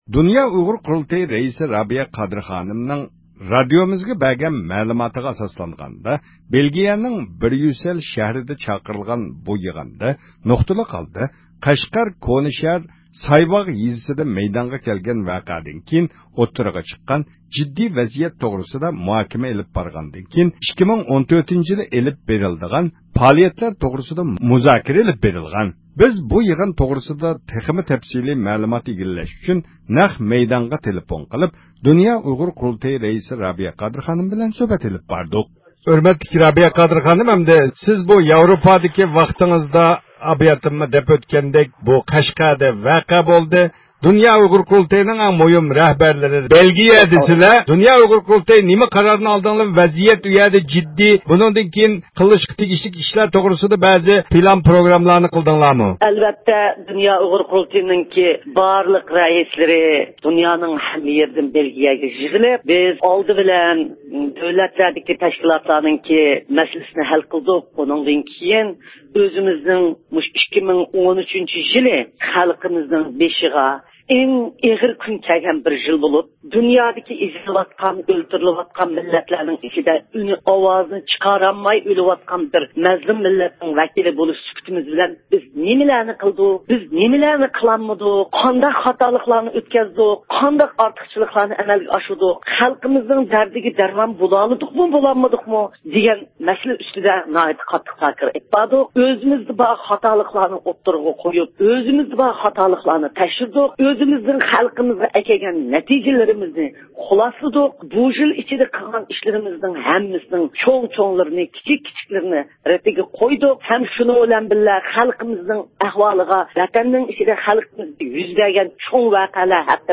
بىز بۇ يىغىن توغرىسىدا تەپسىلى مەلۇمات ئىگىلەش ئۈچۈن نەق مەيدانغا تېلېفون قىلىپ د ئۇ ق رەئىسى رابىيە قادىر خانىم بىلەن سۆھبەت ئېلىپ باردۇق.